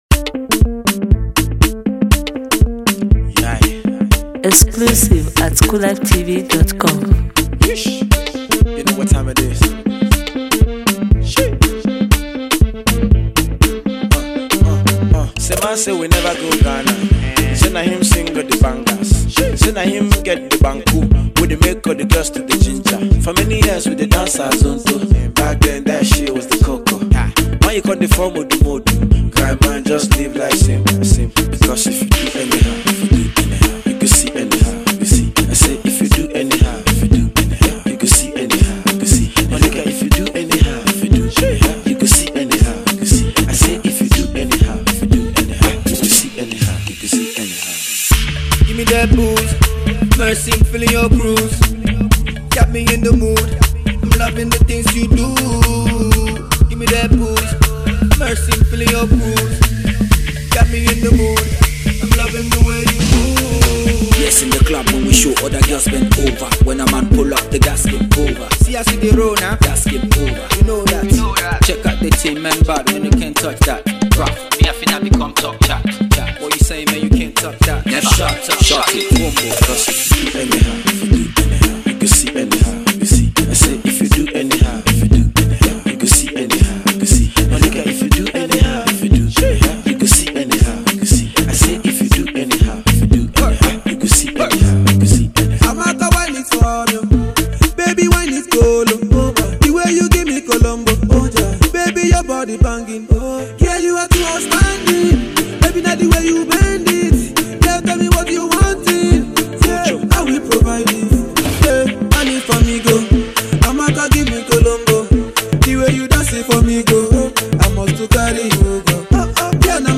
high-octane uptempo cut